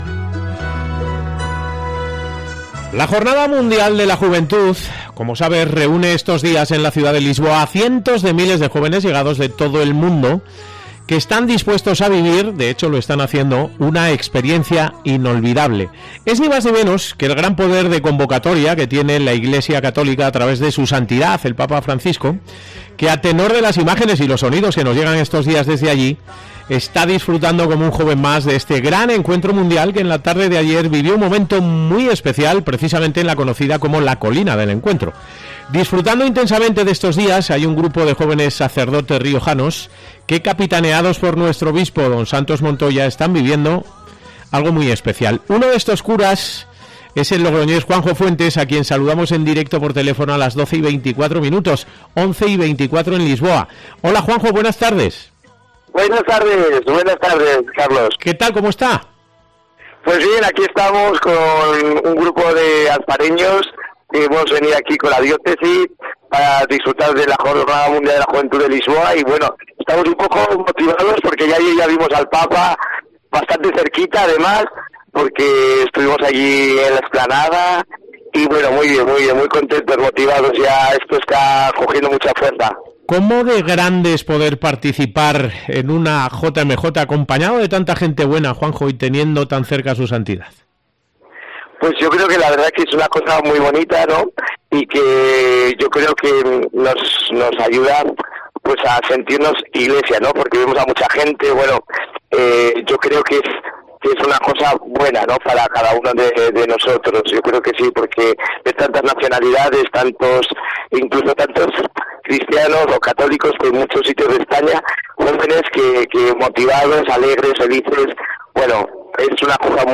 interviene en directo en COPE Rioja, desde Lisboa, en compañía de algunos jóvenes riojanos que participan en la JMJ